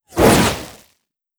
wateryzap2.wav